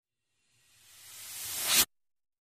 Strange Reversed Air Releases Vary In Pitch, X10